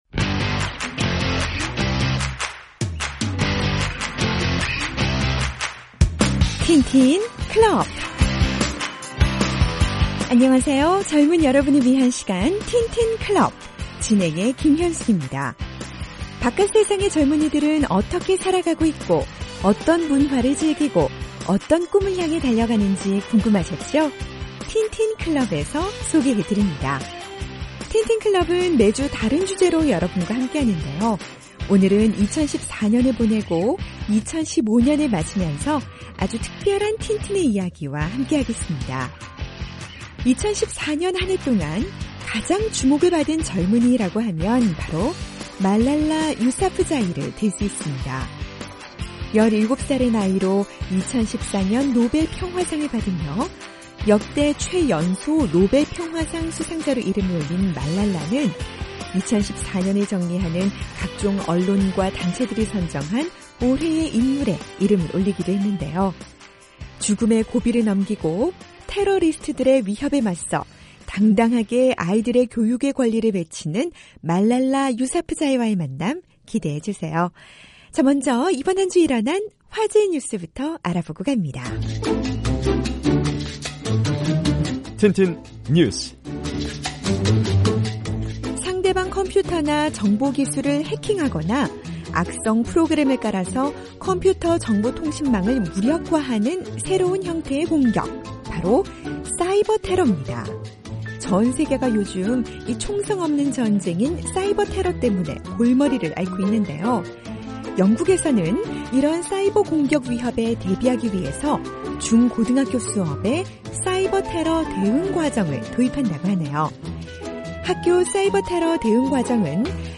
2014년을 보내고 또 2015년을 맞으며 아주 특별한 틴틴을 만나봅니다. 2014년 한 해 동안 가장 주목을 받는 젊은이, 바로 말랄라 유사프자이인데요. 17살의 나이로 2014 노벨 평화상을 받으며 역대 최연소 노벨평화상 수상자로 이름을 올린 10대 인권 운동가 말랄라와의 인터뷰 함께하시죠.